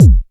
• 2000s Fluffy Electronic Kick Drum Single Hit F Key 129.wav
Royality free steel kick drum sound tuned to the F note. Loudest frequency: 245Hz